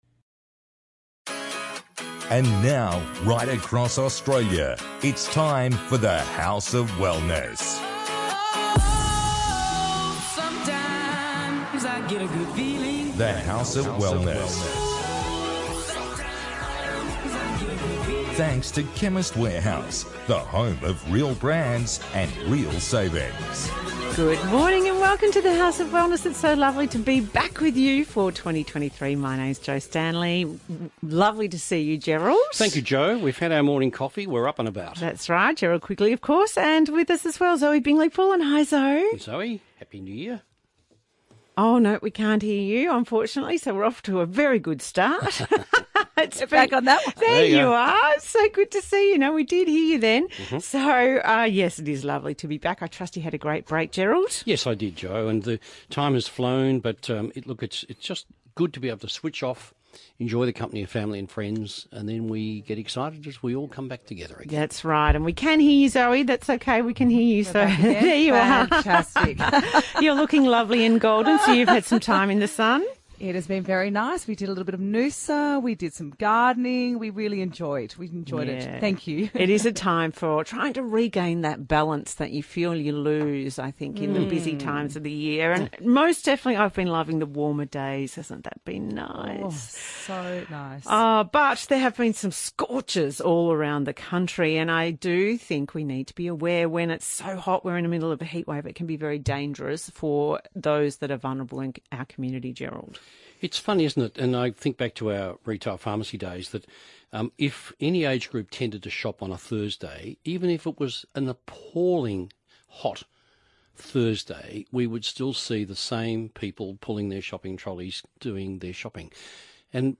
On this week’s The House of Wellness radio show (January 15, 2023) the team discusses: